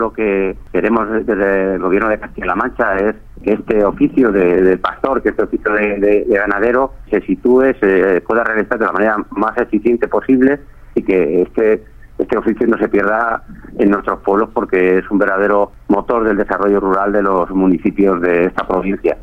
El director provincial de Agricultura, Medio Ambiente y Desarrollo Rural en Guadalajara, Santos López habla de la importancia del pastore, en relación con los cursos sobre esta temática organizados por el Gobierno regional.